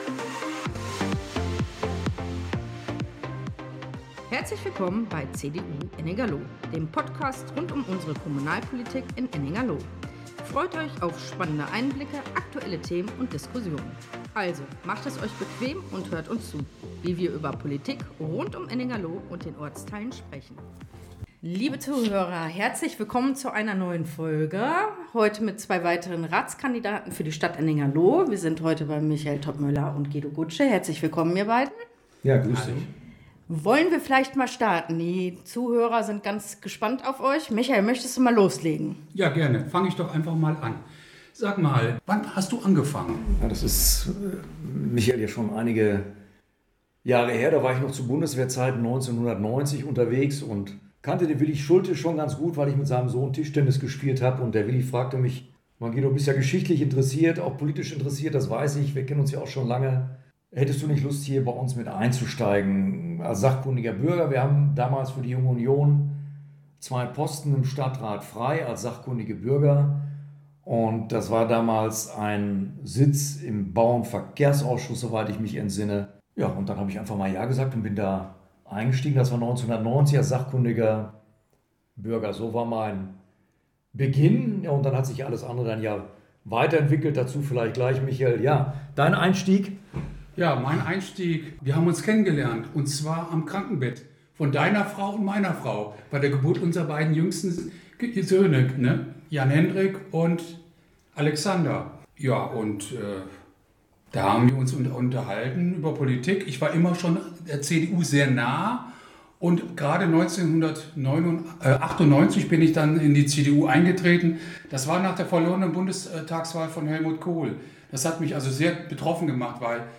Instrumental Lively-Instrumental Intro und Outro